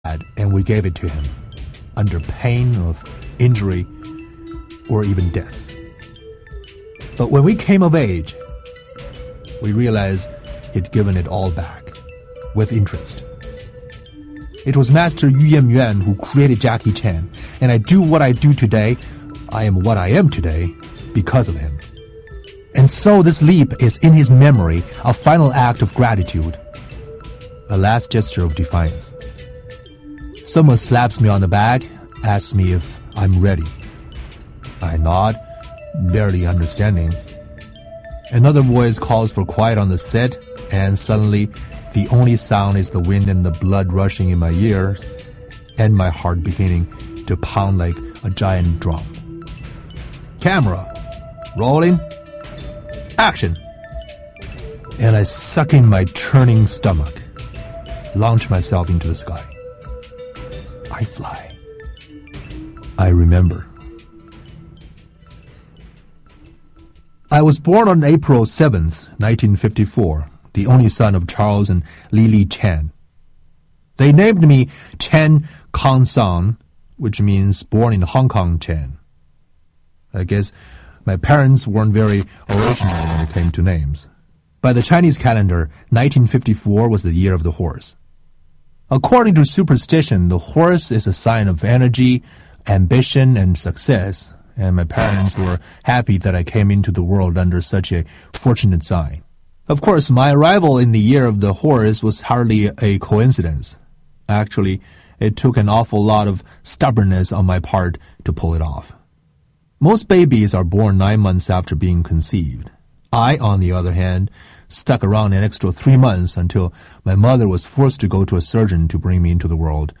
Tags: Autobiography Audio Clips Autobiography Autobiography sounds Autobiography Audio history